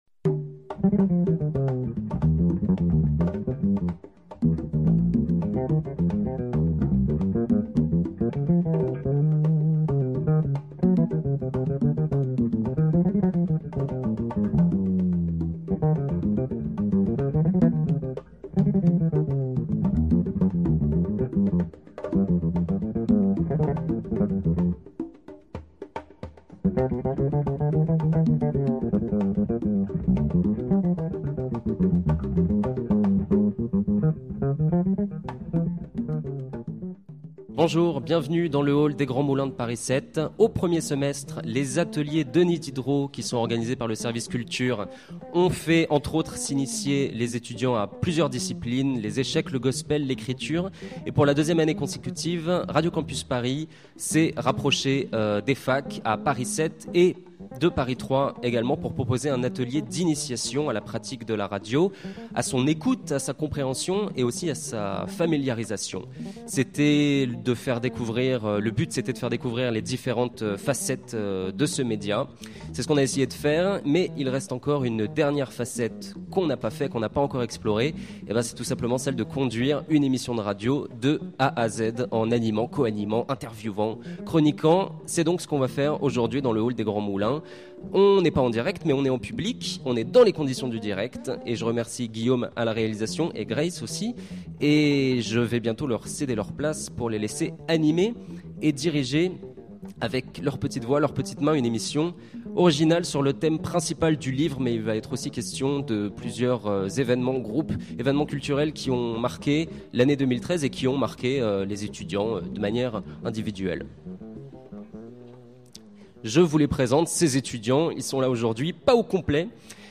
Emission en public à Paris Diderot
L’atelier radio organisé à l’université Paris Diderot – Paris 7 s’est terminé avec l’enregistrement d’une émission jeudi 13 février, entre midi et deux, dans le hall A des Grands Moulins.
podcast-emission-en-public-a-a6bb937b.mp3